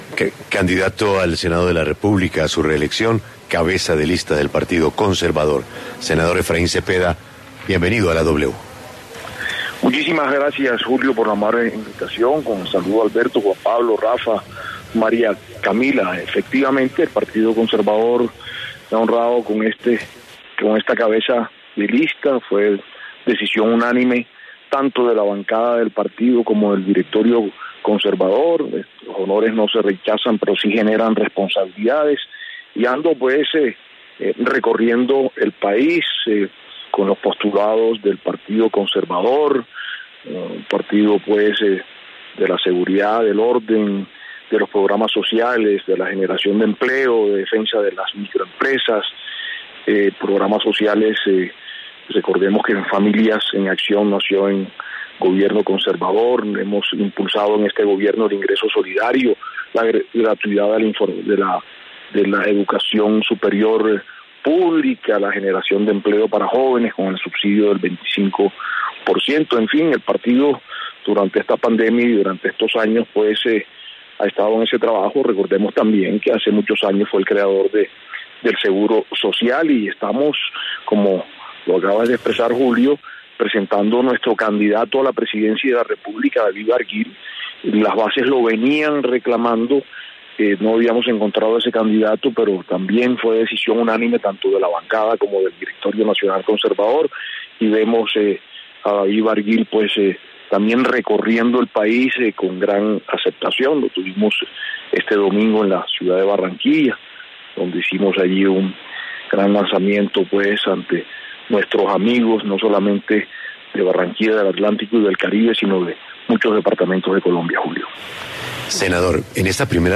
Efraín Cepeda habló en La W sobre la cabeza de lista al Senado por el Partido Conservador